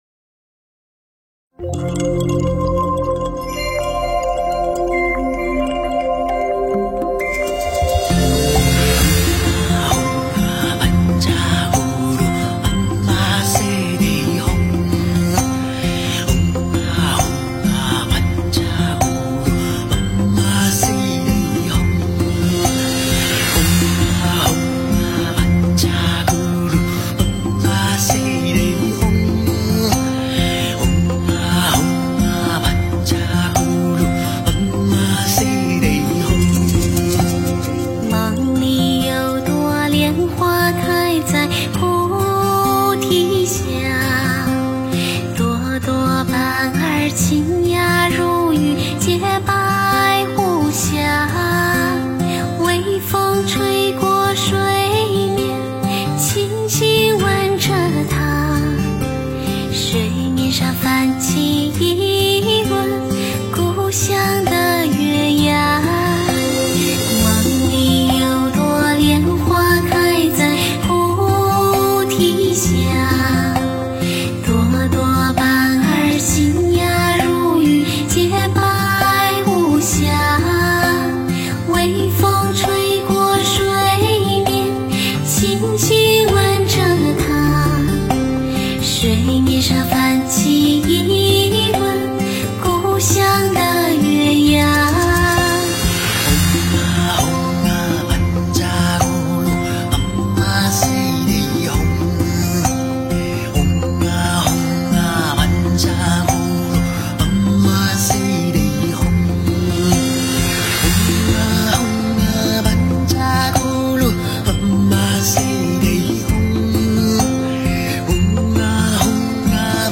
佛音 诵经 佛教音乐 返回列表 上一篇： 我是佛前一朵青莲 下一篇： 阿妈佛心上的一朵莲 相关文章 自性歌-六祖坛经择句 自性歌-六祖坛经择句--耕云导师曲...